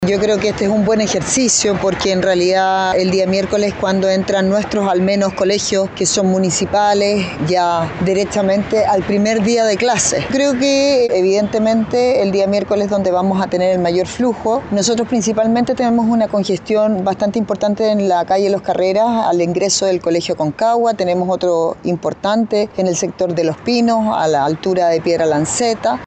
Por su parte la alcaldesa de Quilpué, Carolina Corti, detalló las principales avenidas en donde se producen tacos en esta comuna tras el regreso a clases.